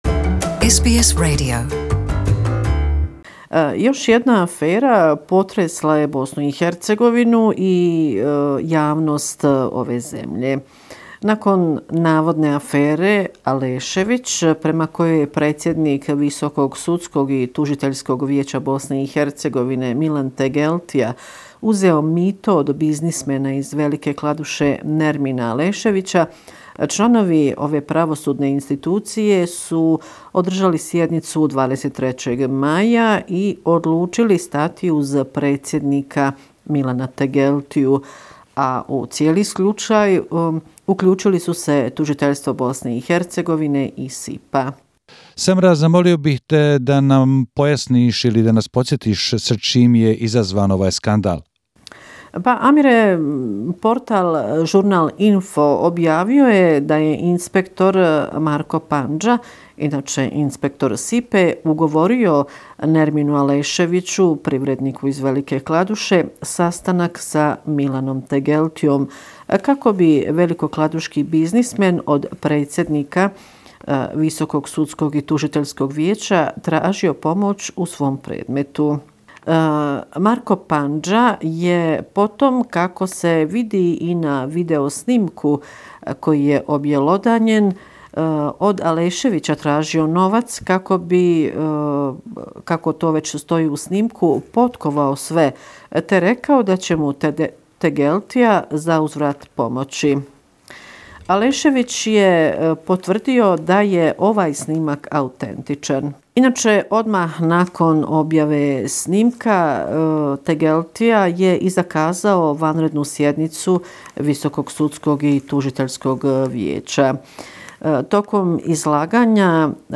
Bosnia and Herzegovina, weekly report, May 26, 2019